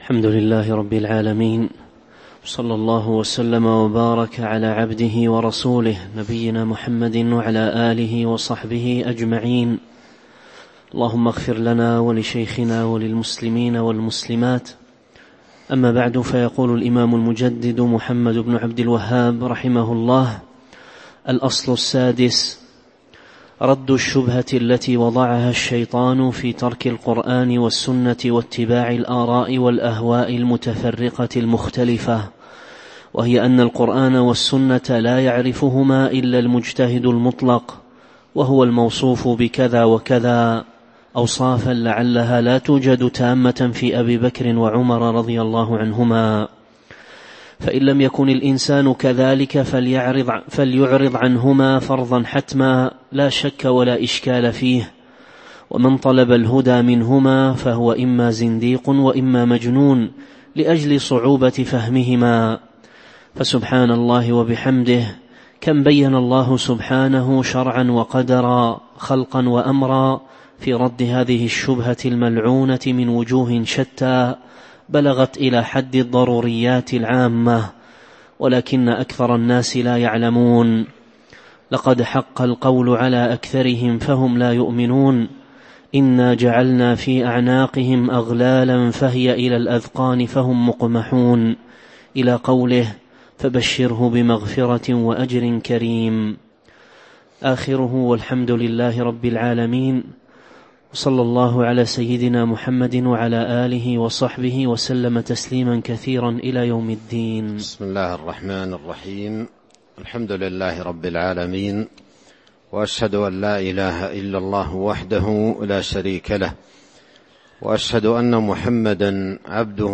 تاريخ النشر ٢٨ صفر ١٤٤٥ هـ المكان: المسجد النبوي الشيخ